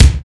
Kick (BBO).wav